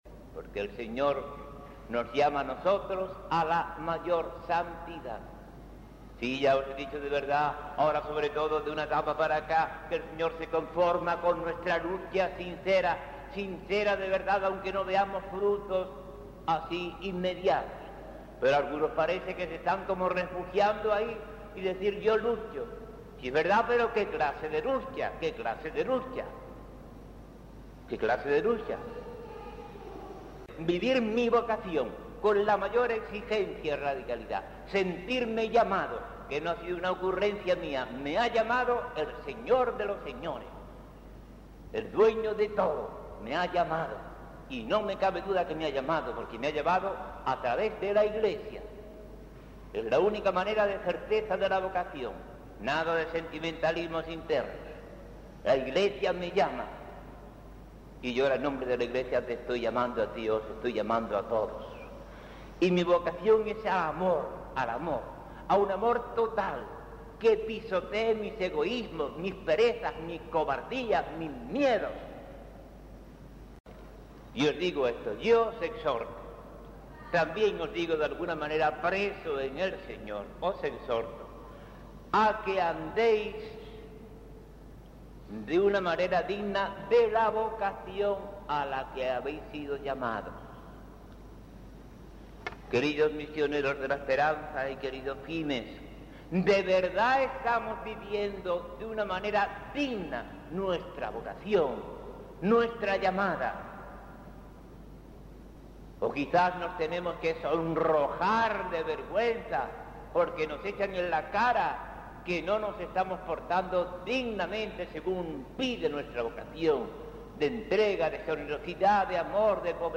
Charlas